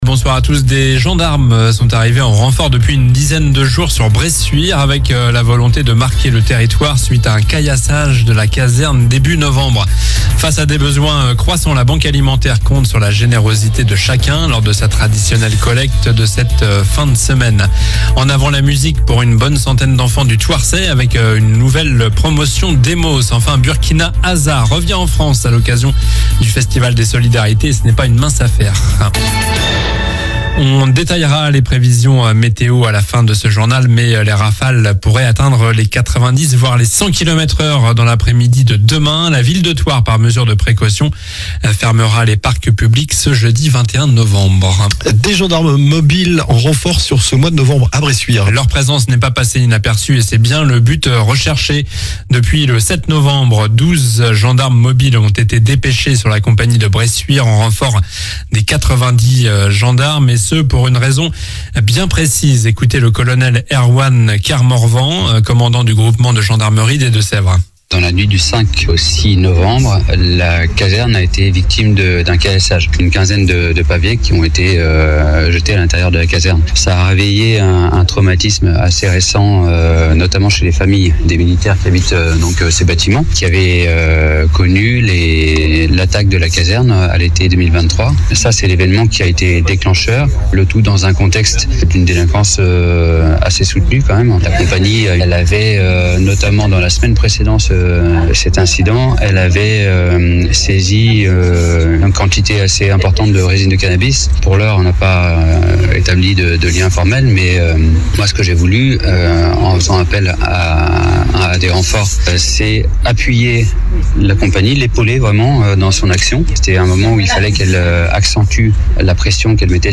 Journal du mercredi 20 novembre (soir)